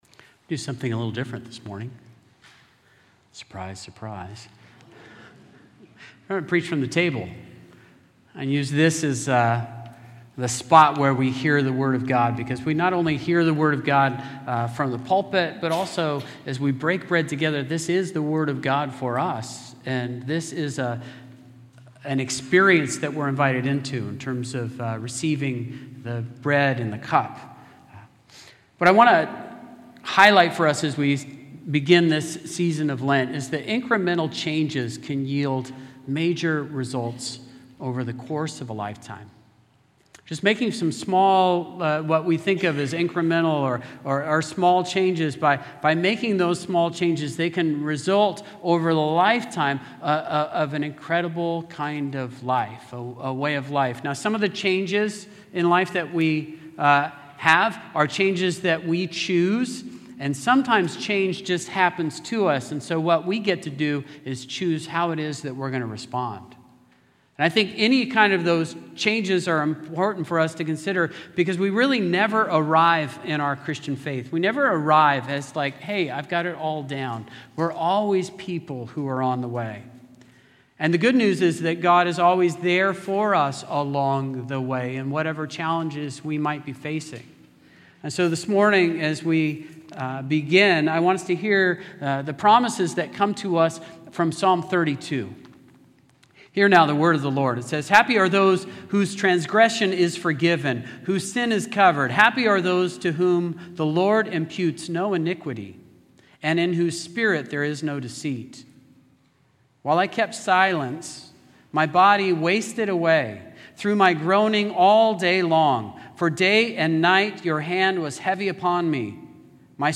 Play Rate Listened List Bookmark Get this podcast via API From The Podcast You are listening to the St. Peter's By-the-Sea Presbyterian Church sermon podcast.